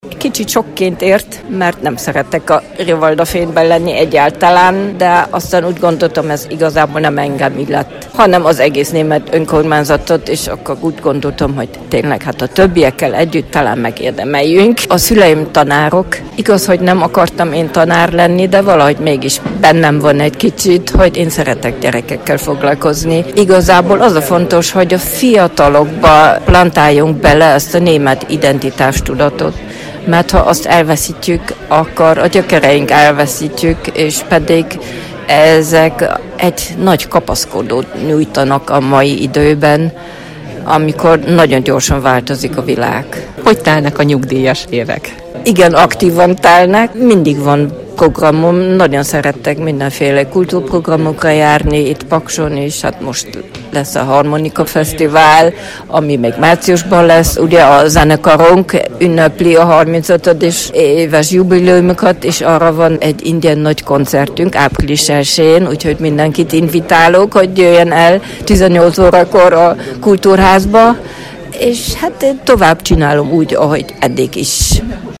Városi ünnepséget tartottak a hétvégén Pakson🔊